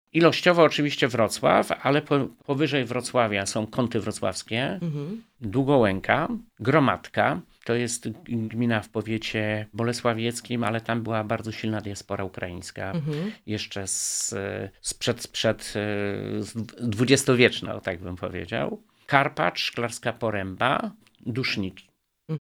Wojewoda Jarosław Obremski wymienia, które gminy przyjęły najwięcej uchodźców.